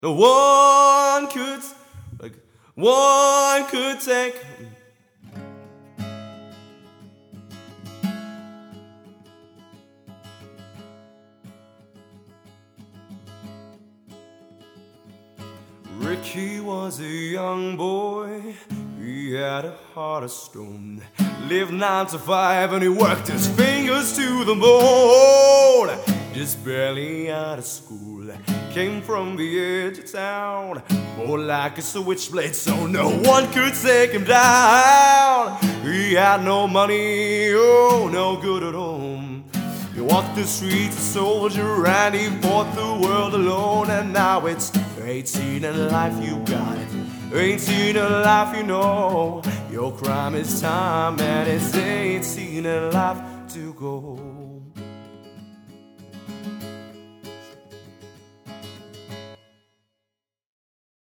Mångsidig Trubadur med erfarenhet och bred repertoar
• Trubadur